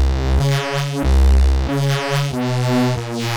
i made it with fruity loops starting from that vanguard reece :
reese.wav